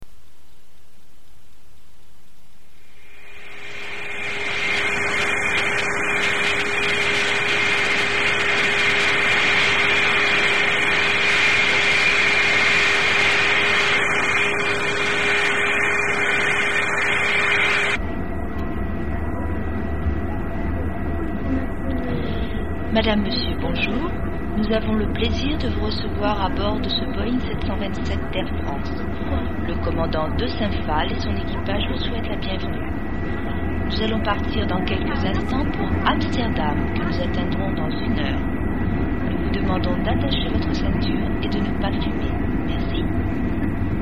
Où peut-on entendre cette annonce ?
annonce2.MP3